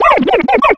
Cri de Brindibou dans Pokémon Soleil et Lune.